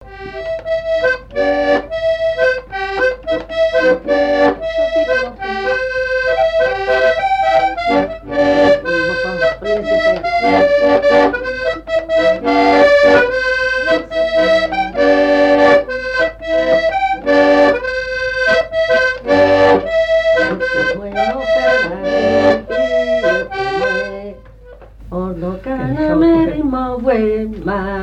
Mémoires et Patrimoines vivants - RaddO est une base de données d'archives iconographiques et sonores.
à marcher
fiançaille, noce
Genre laisse
Pièce musicale inédite